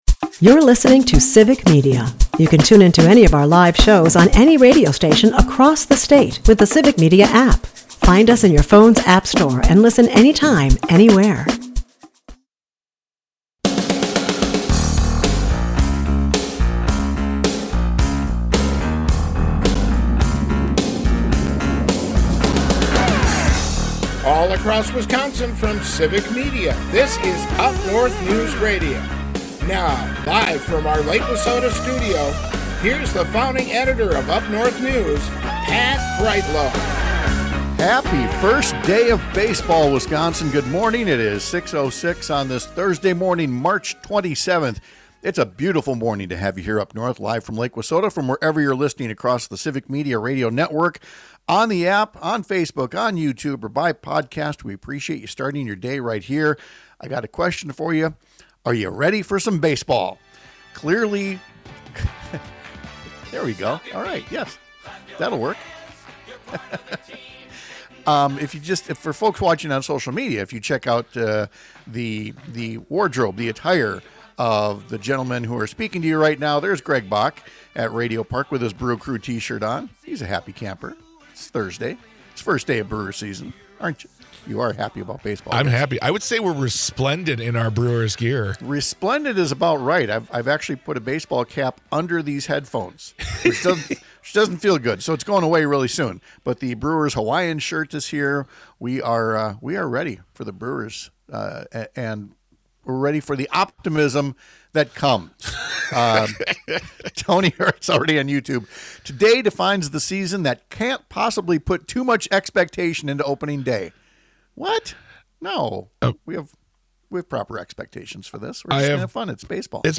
airs on several stations across the Civic Media radio network, Monday through Friday from 6-8 am.